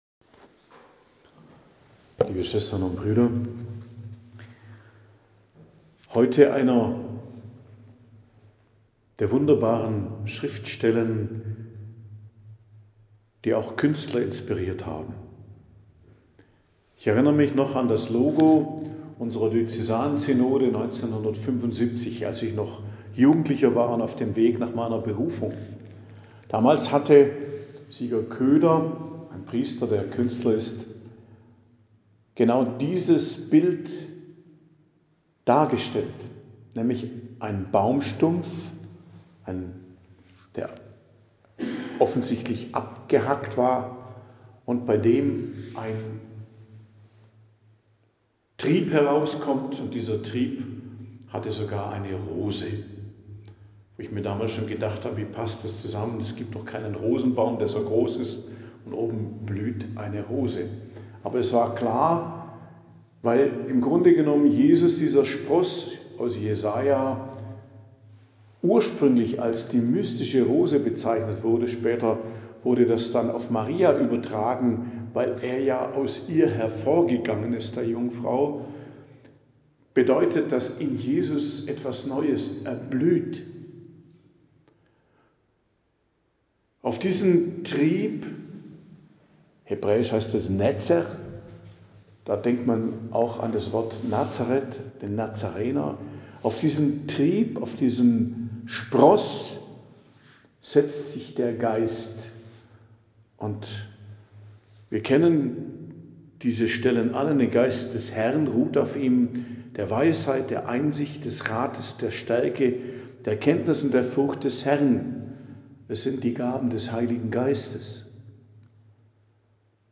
Predigt am Dienstag der 1. Woche im Advent, 3.12.2024 ~ Geistliches Zentrum Kloster Heiligkreuztal Podcast